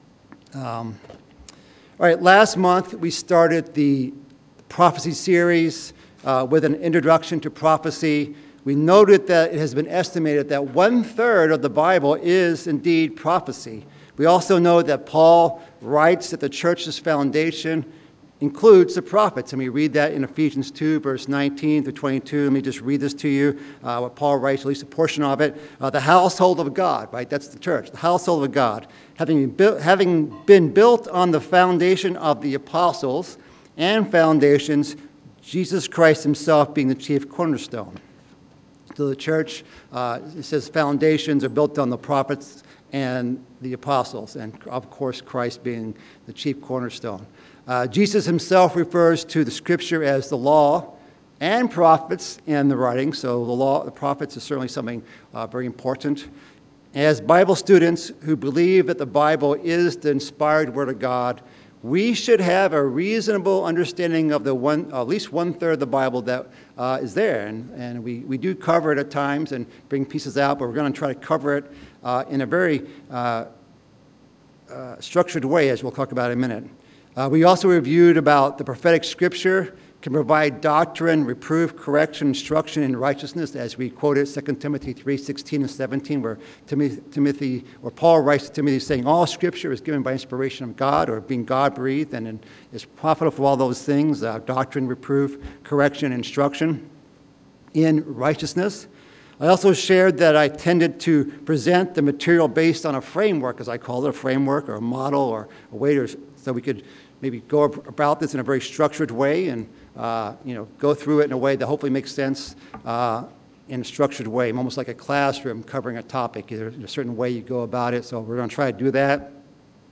UCG Chicago 2025 Sermon Archive